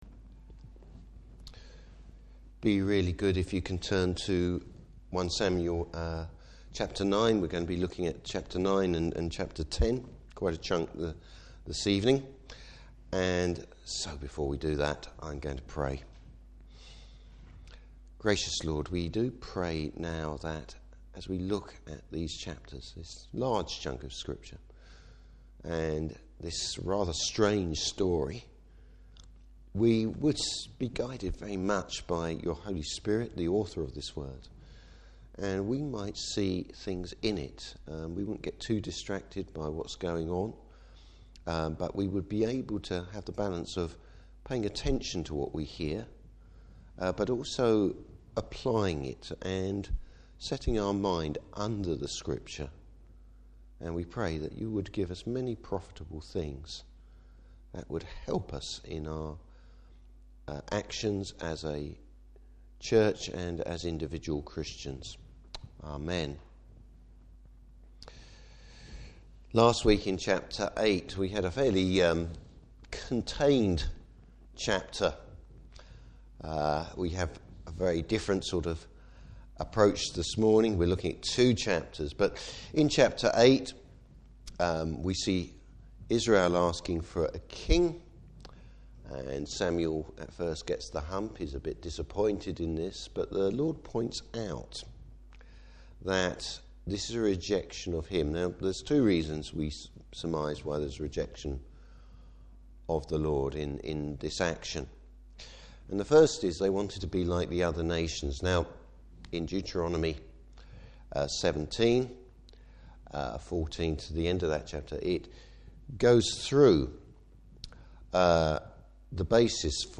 Service Type: Evening Service A strange set of circumstances, or the providence of God.